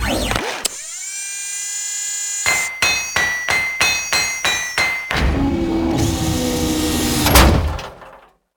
cargodrone.ogg